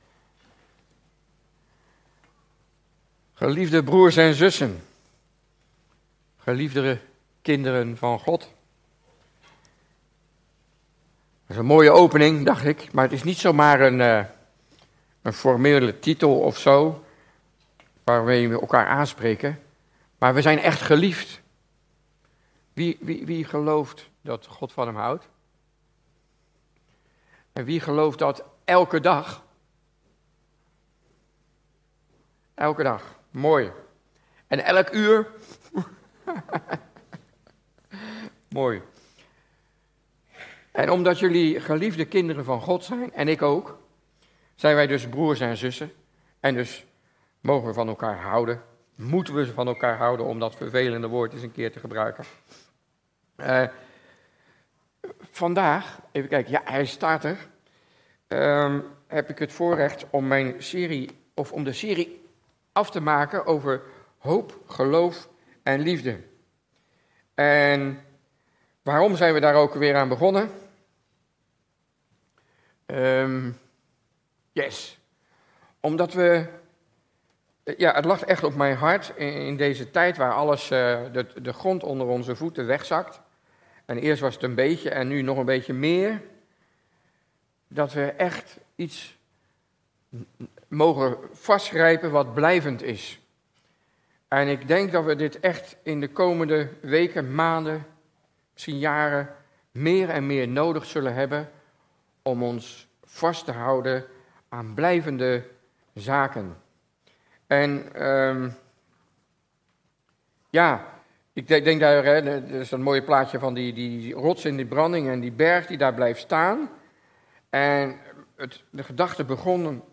Toespraak 15 mei: Blijvend houvast: Liefde - De Bron Eindhoven